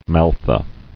[mal·tha]